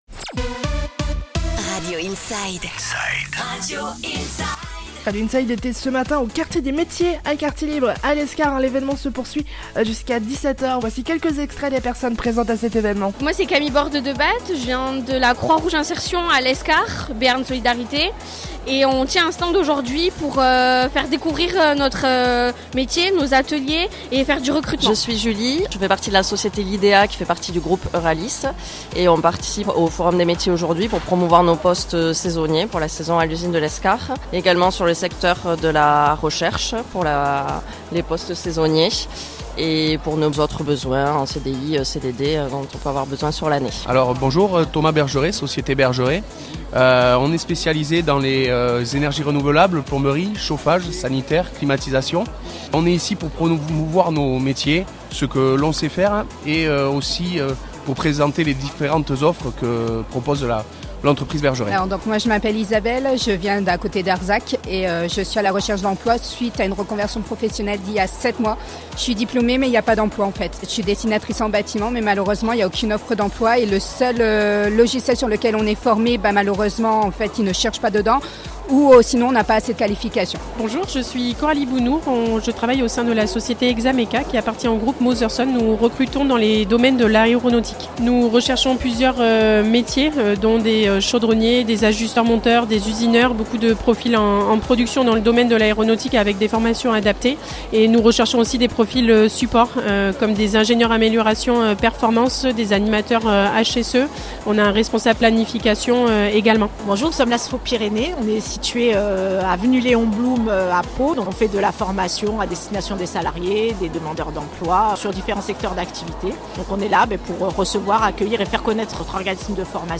Radio Inside était sur place ce matin au Quartier des Métiers à Lescar pour reccueillir plusieurs témoignages d'entreprises à la recherche de nouveaux talents, ainsi que des organismes, des centres de formations offrant un accompagnement professionnnel.